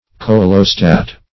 coelostat \coe"lo*stat\ n.